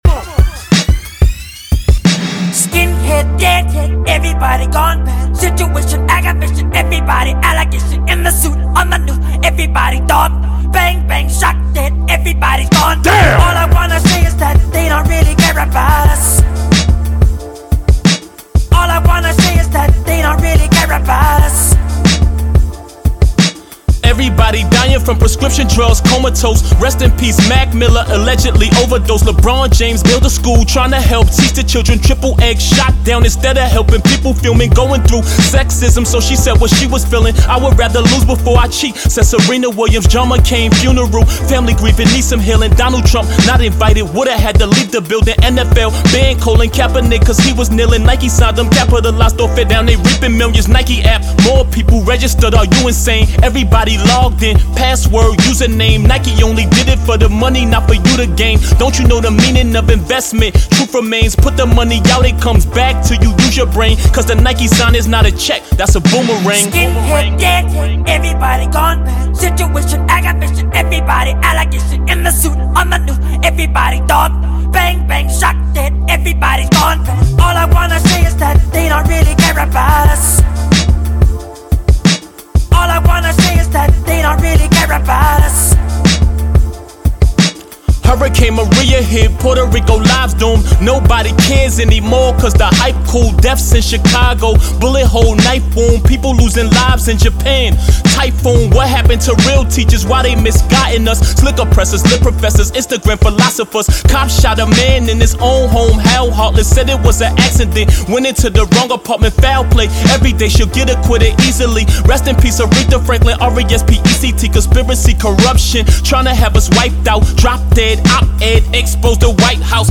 хип-хоп композиция